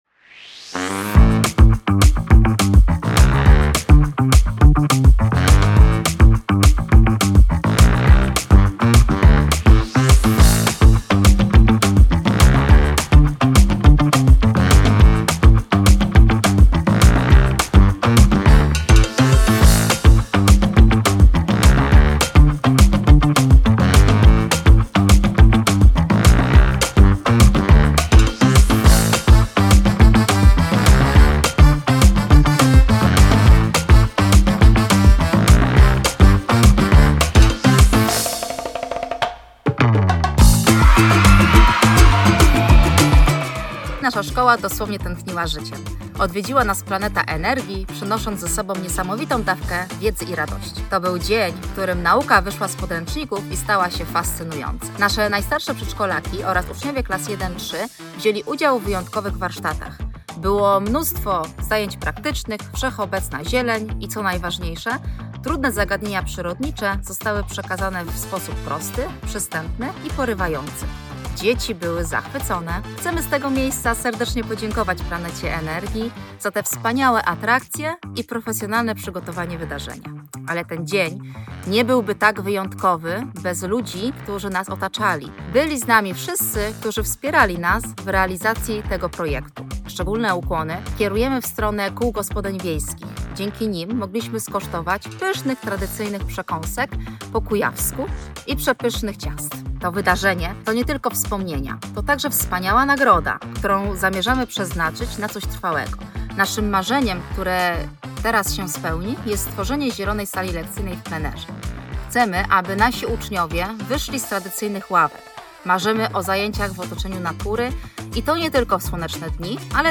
Relacja z BioPikniku w Smólniku